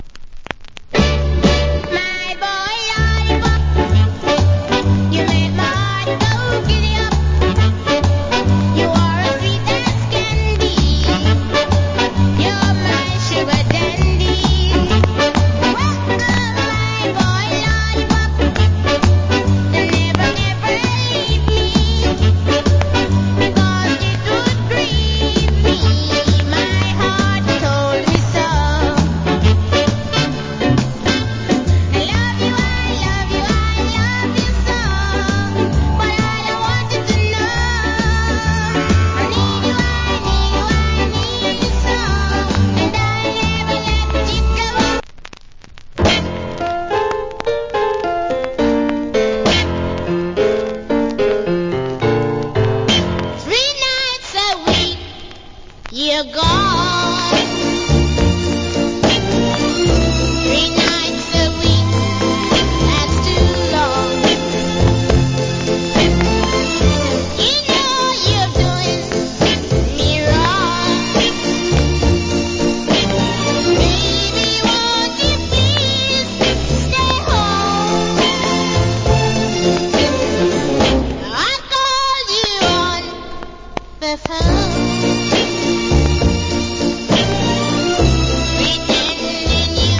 Good Female Ska Vocal.